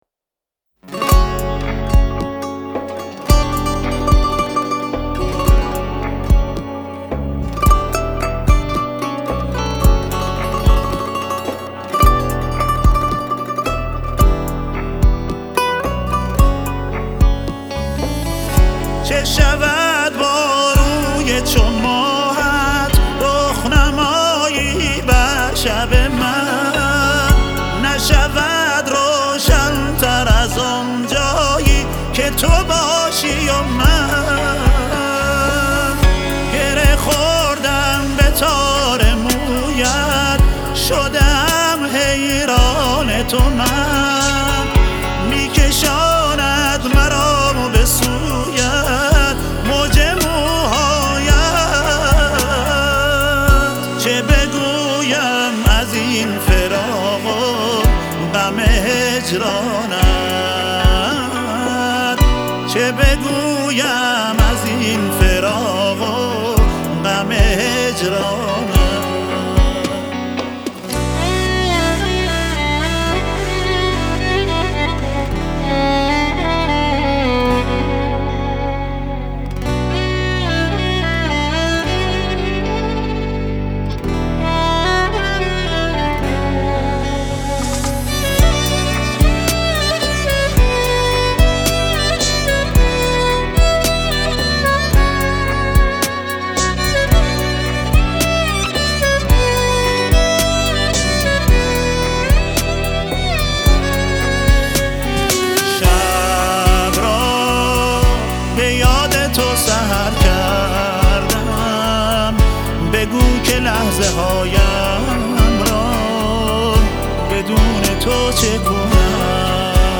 گیتار
سه تار
سنتور
ویولن
هم خوان